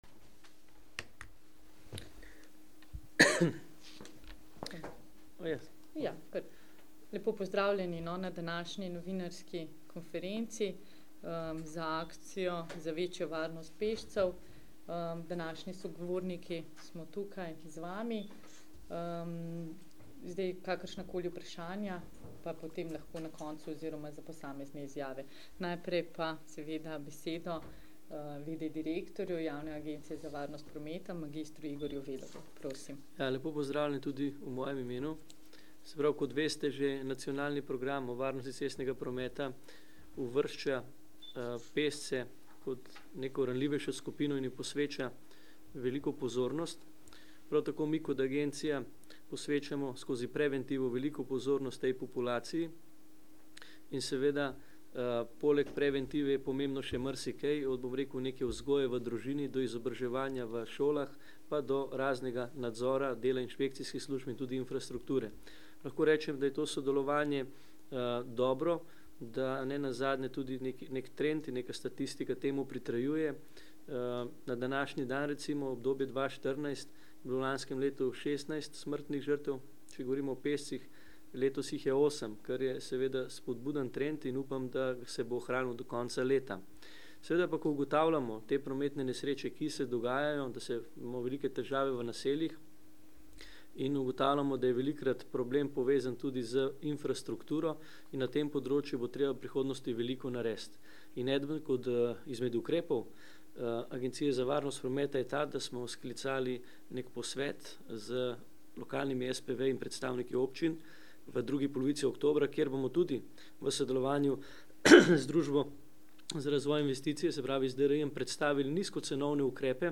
30.9.2014Na današnji novinarski konferenci smo predstavili aktivnosti za večjo varnost pešcev v okviru nacionalne akcije Pešci, ki bo potekala od 1. do 12. oktobra 2014 pod okriljem Nacionalnega programa varnosti cetsnega prometa 2013 - 2022.